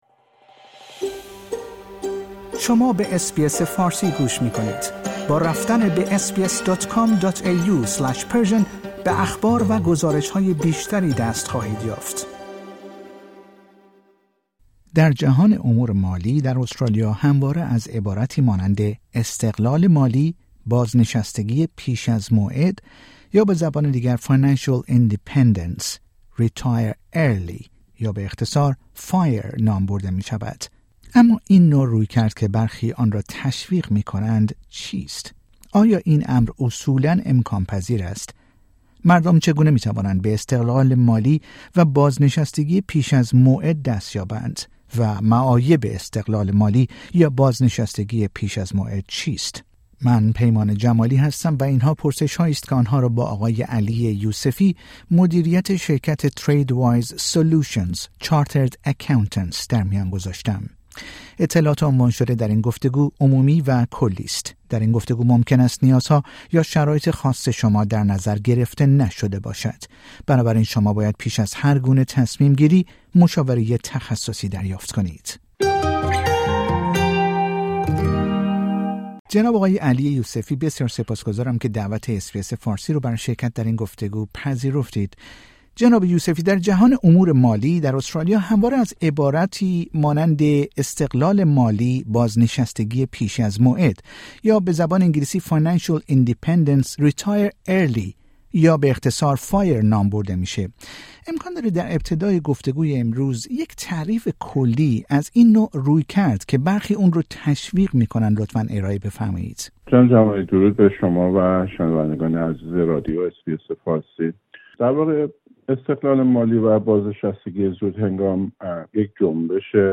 در گفتگو با رادیو اس بی اس فارسی به این پرسش ها پاسخ می دهد.